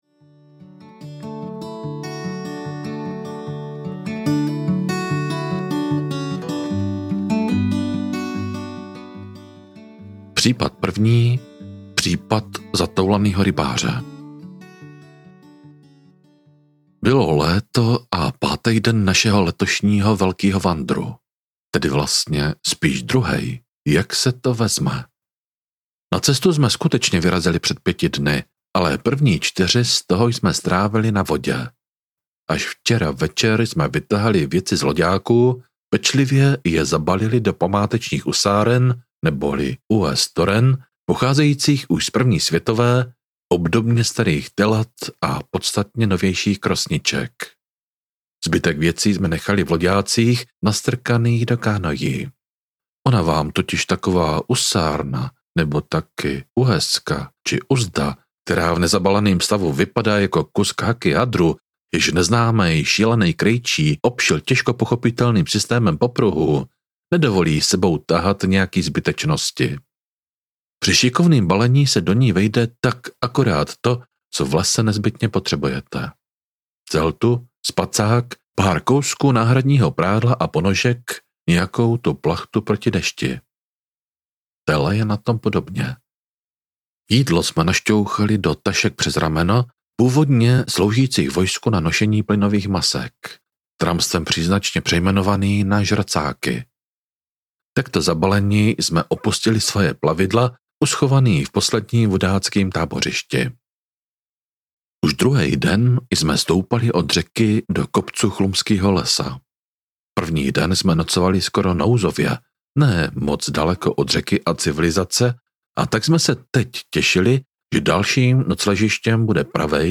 Případ zatoulanýho rybáře audiokniha
Ukázka z knihy